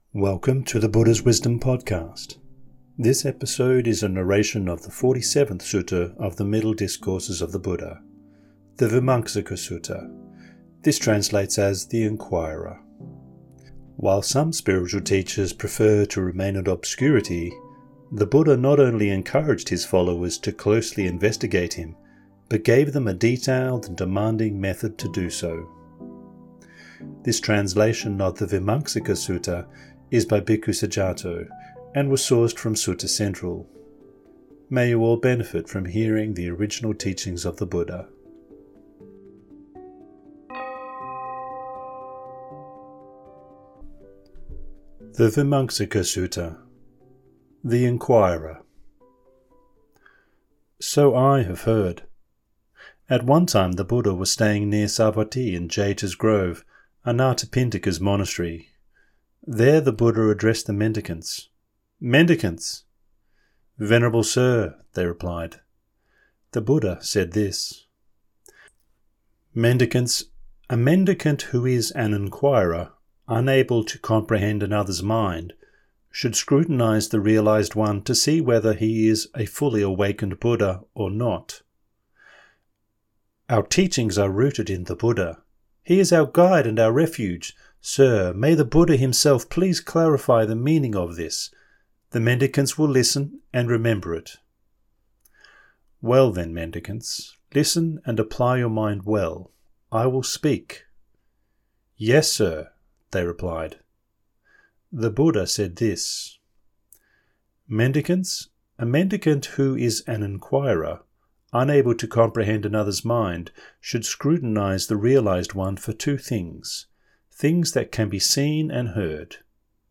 This episode is a narration of the 47th Sutta of the Middle Discourses of the Buddha, The Vīmaṁsaka Sutta, which translates as “The Inquirer”. While some spiritual teachers prefer to remain in obscurity, the Buddha not only encouraged his followers to closely investigate him, but gave them a detailed and demanding method to do so.